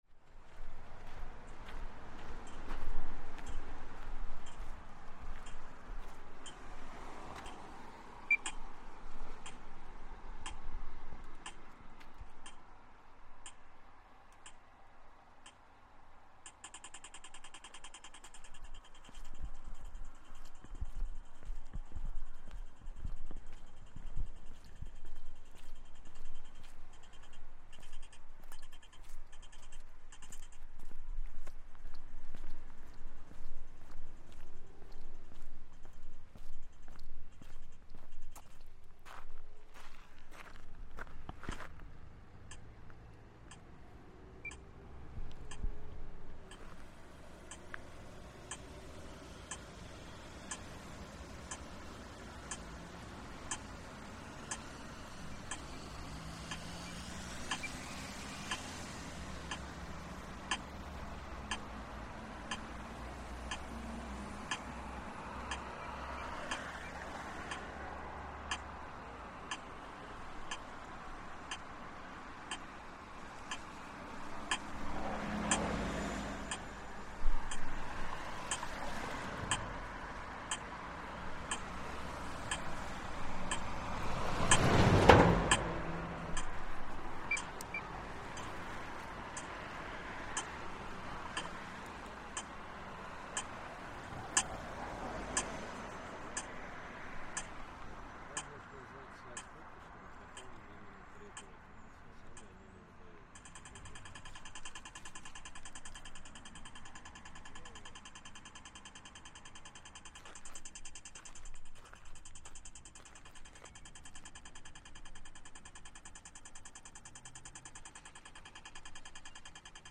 The distinctive ticking of a pedestrian crossing on a busy road in Tallinn, which gets quicker to let you know it's time to cross, and slower when it's time to wait.